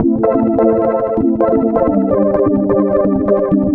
Bubble Sync Am 128.wav